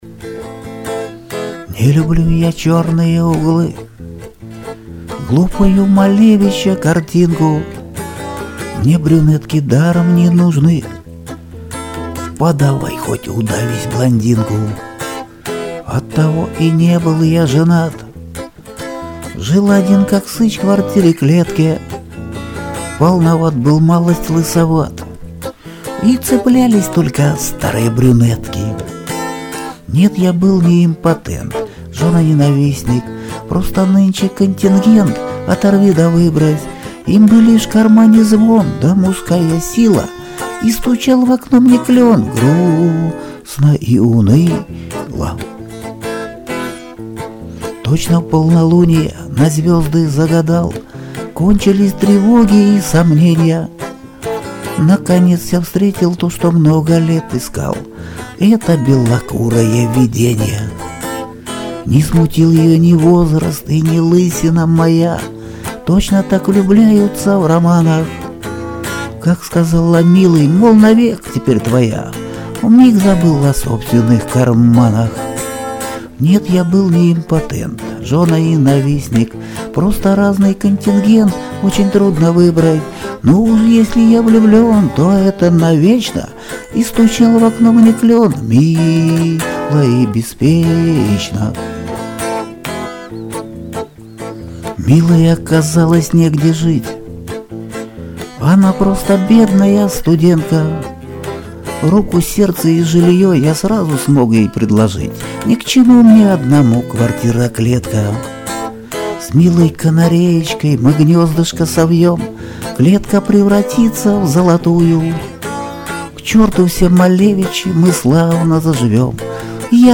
Музыкальный хостинг: /Шансон